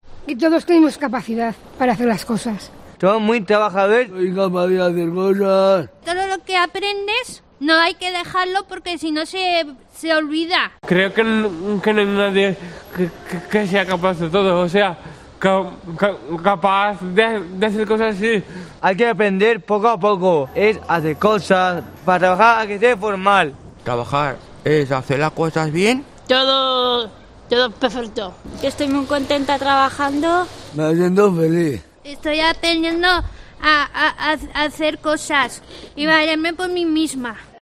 Testimonios de jóvenes con autismo sobre la importancia de poder trabajar y ser autónomos